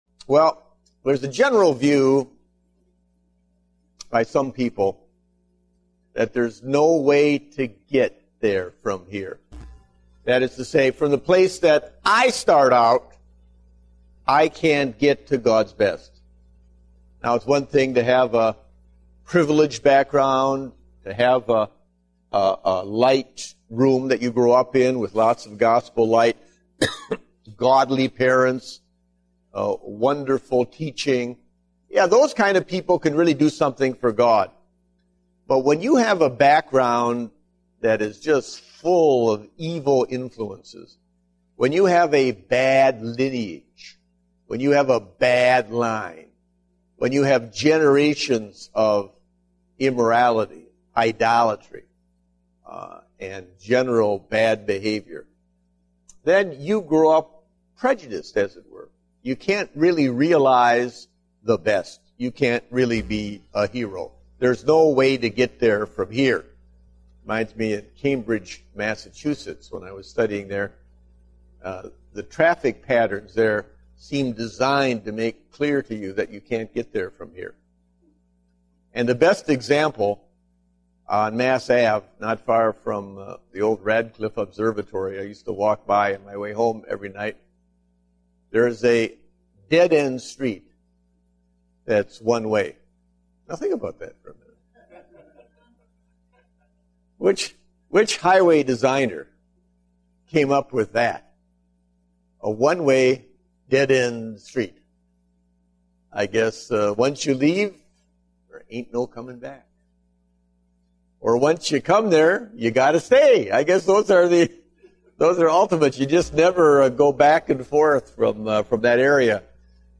Date: March 7, 2010 (Adult Sunday School)